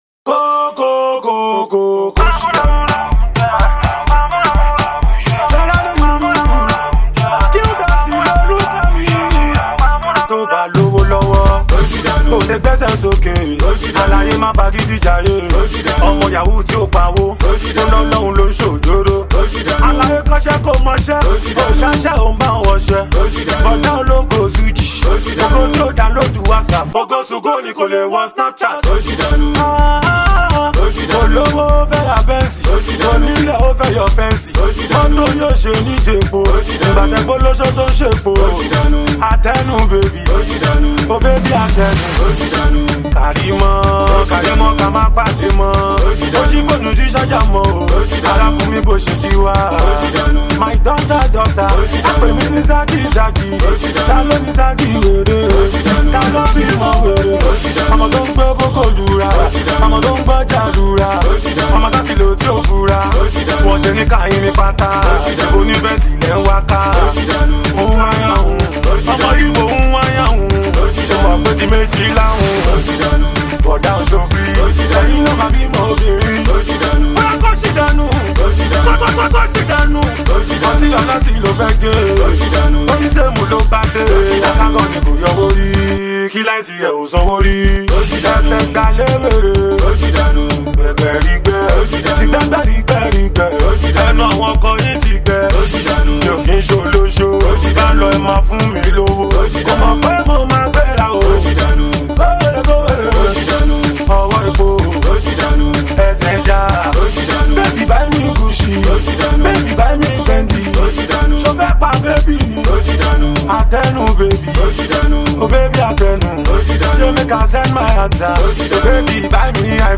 uptempo